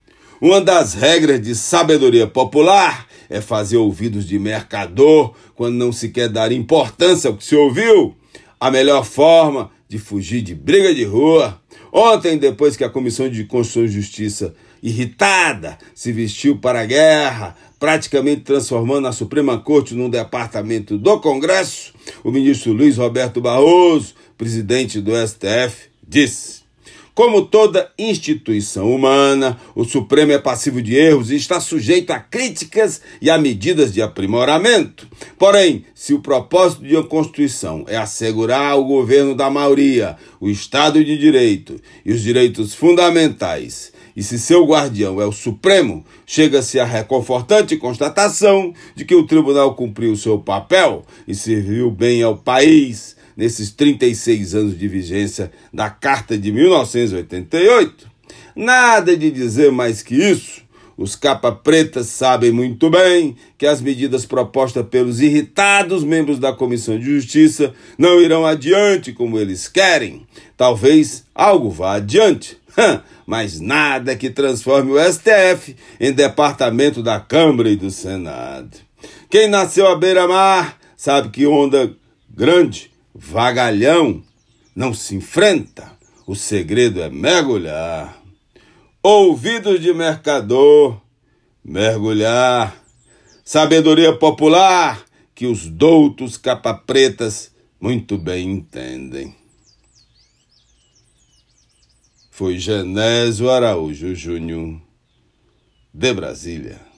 Comentário desta sexta-feira (11/10/24)
direto de Brasília.